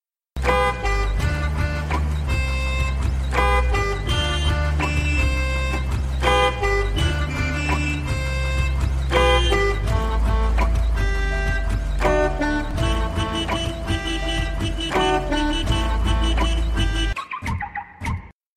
Sound Effects
car_radio.mp3